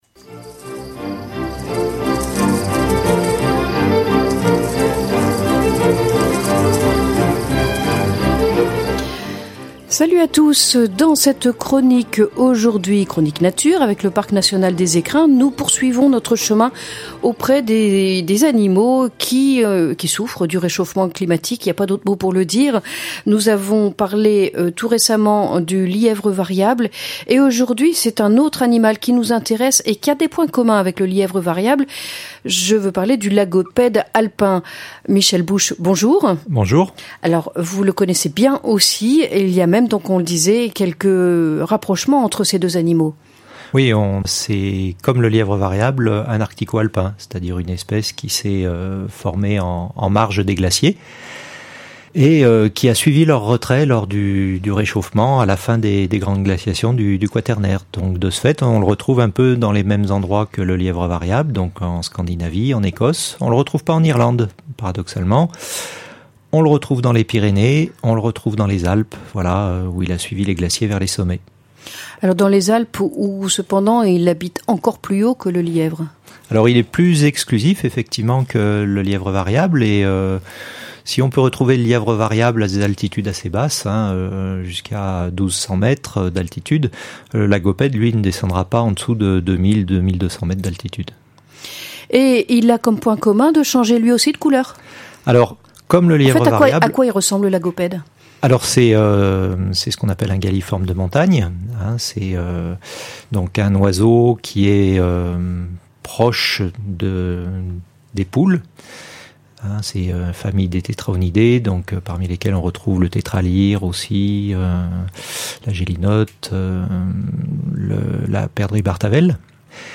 • Chronique nature